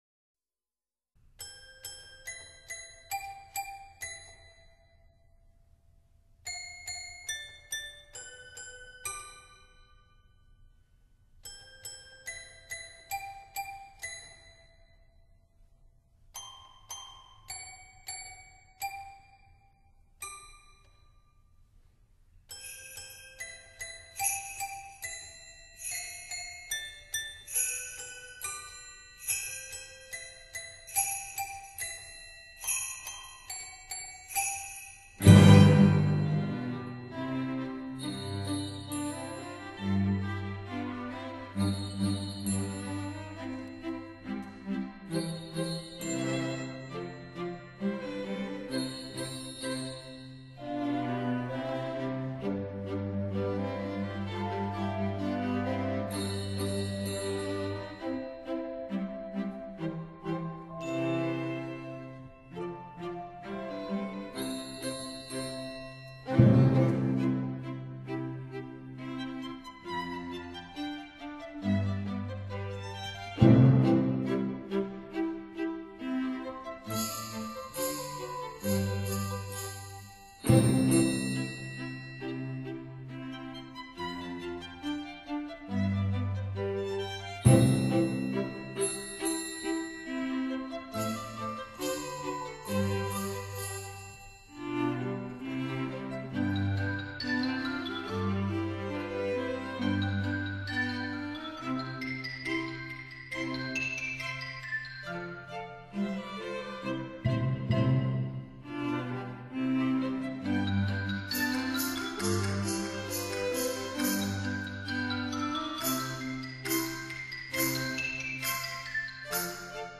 但是卻增加了不少打擊樂的份量，而且並不過分，讓樂曲聽起來生色不少。
採用世界著名兩組不同樂器之四重奏組合而成，變成新穎卓越的八人組合。
這音樂廳被譽為美國東部最佳音響效果之音樂廳。